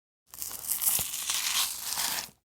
Free SFX sound effect: Melon Peel.
yt_RFVhE-deEf4_melon_peel.mp3